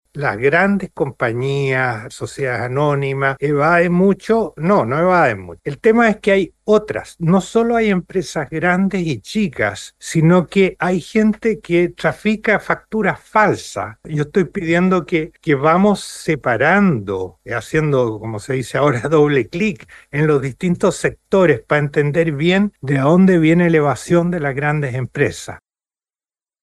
Javier Etcheberry en Comisión de Hacienda de la Cámara